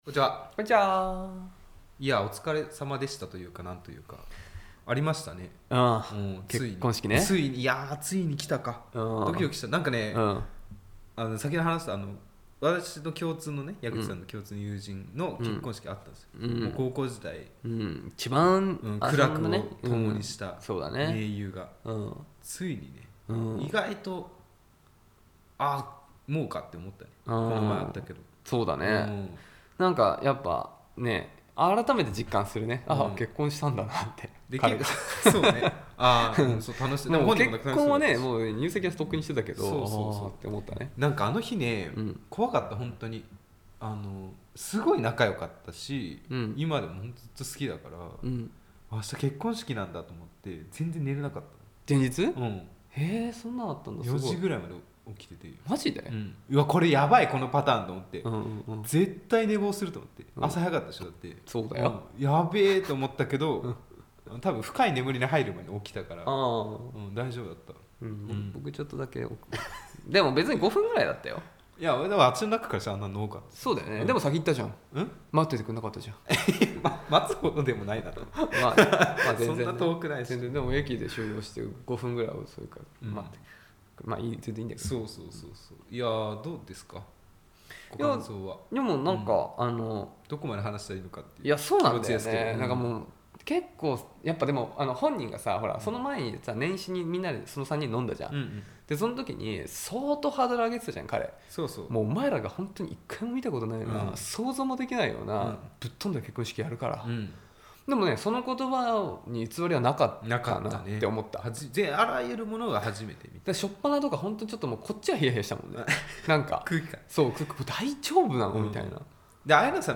恋の街 中野よりアラサー男が恋愛トークをお届けします！